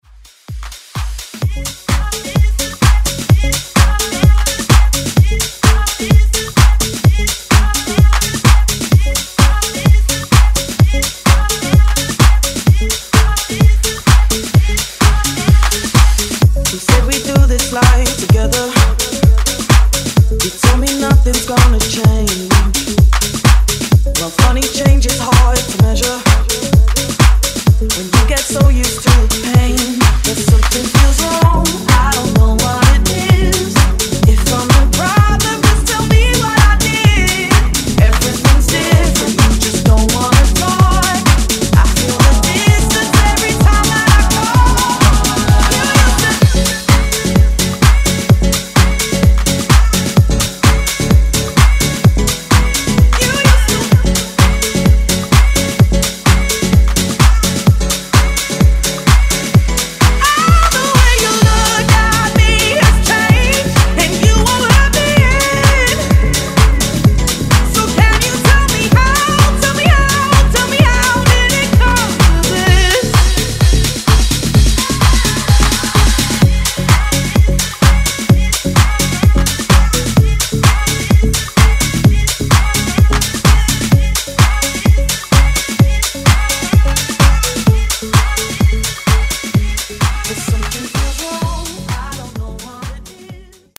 Genres: DANCE , RE-DRUM , TOP40 Version: Clean BPM: 128 Time